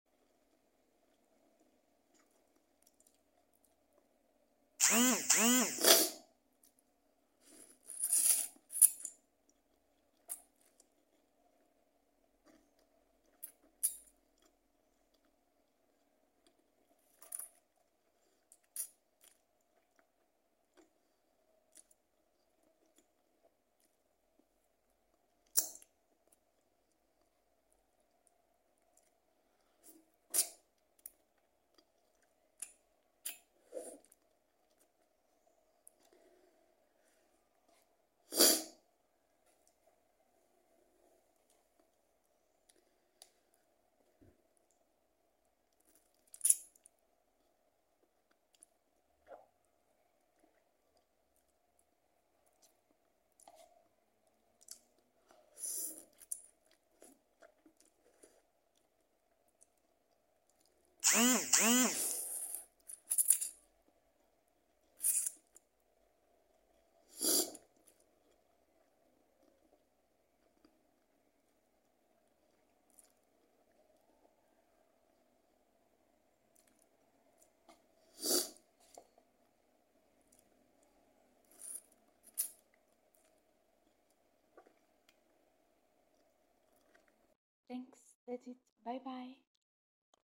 Eating Lamen 👩‍🍳🍜😋 Mukbang sound effects free download
Eating Lamen 👩‍🍳🍜😋 - Mukbang ASMR - Part 3